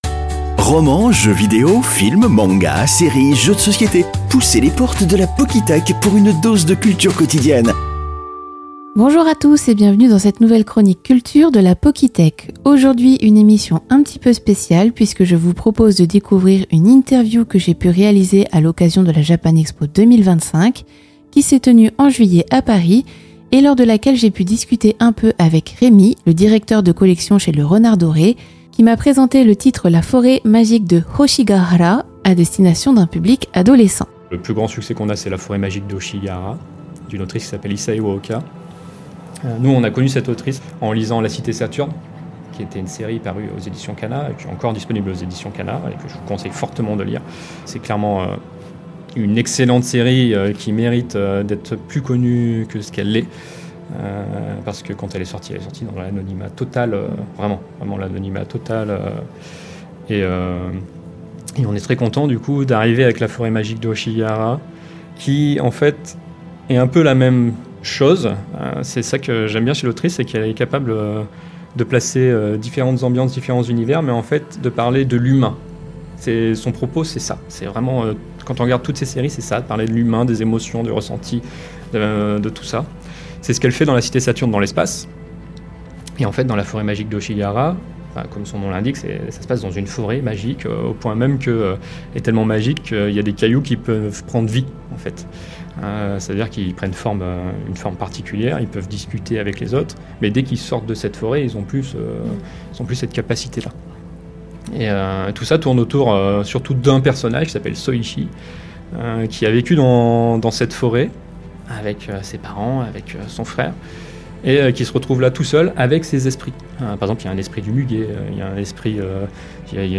La Pockythèque - Interview Le Renard doré (partie 6) - Neptune FM